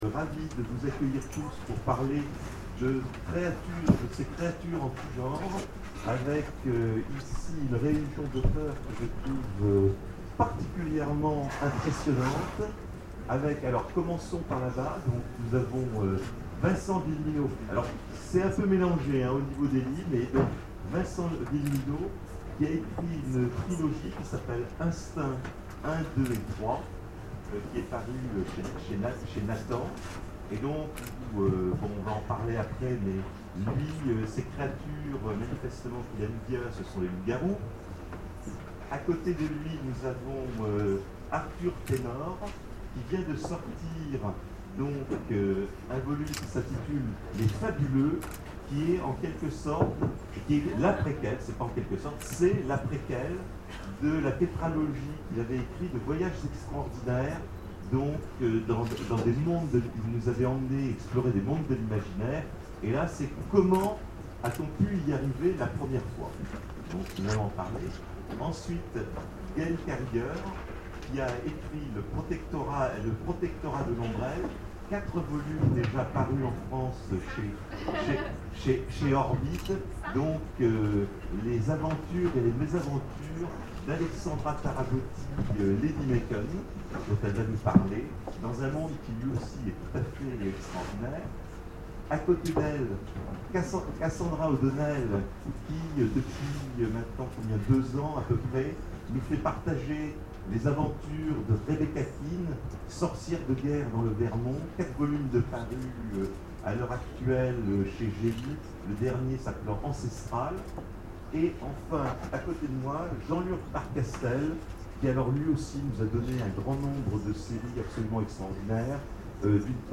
Imaginales 2013 : Conférence Créatures en tous genres !